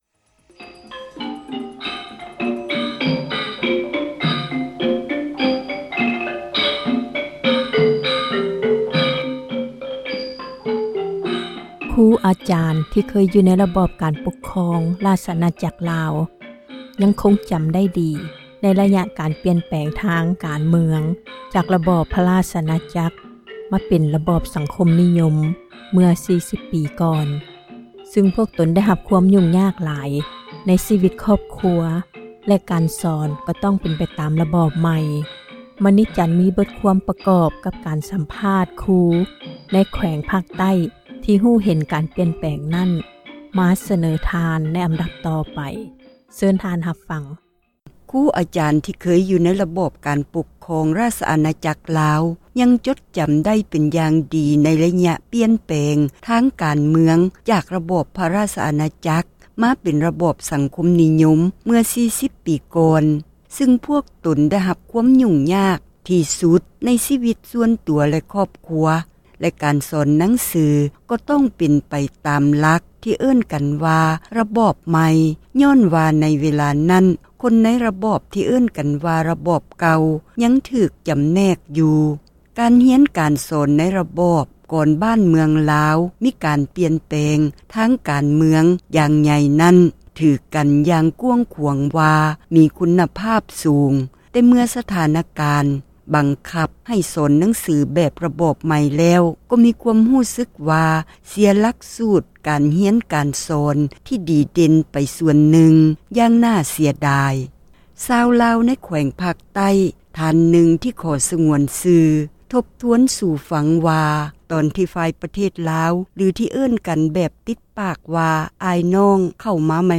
ສັມພາດນາຍຄຣູໃນແຂວງ ພາກໃຕ້